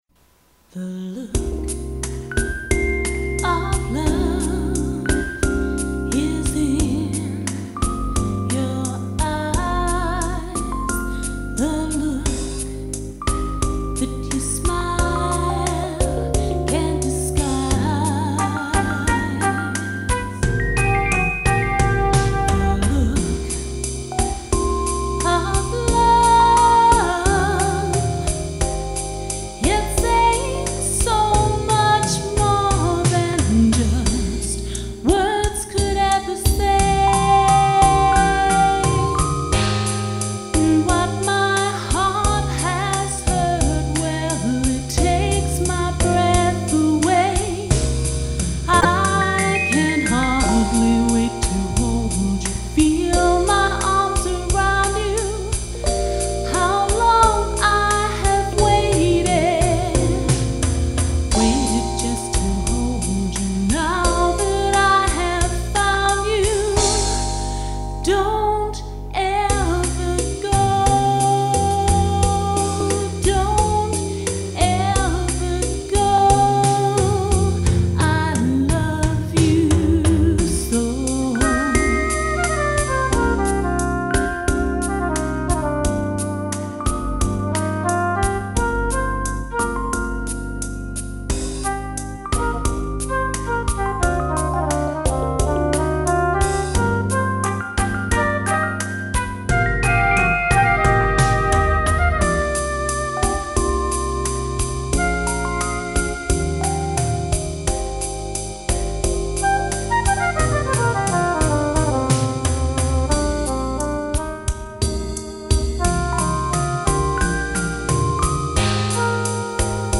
Saxophonist
Jazz standards to modern contemporary